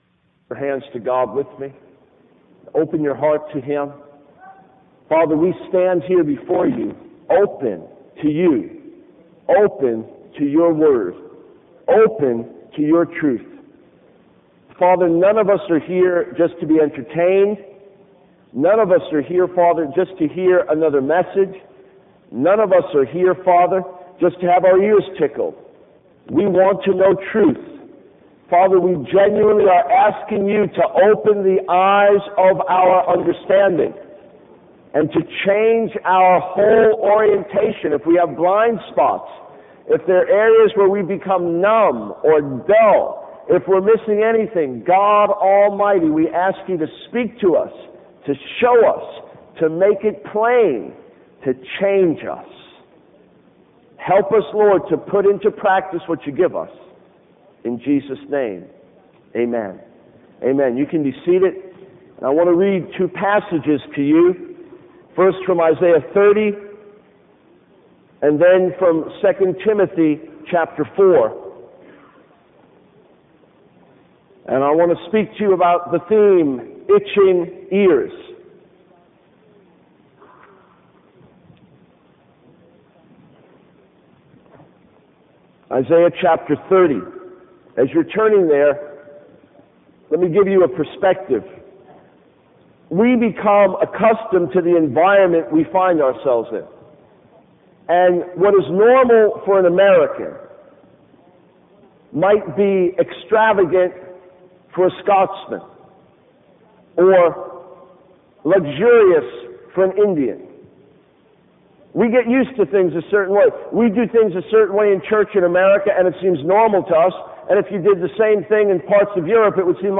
In this sermon, the preacher emphasizes the importance of love and warning others.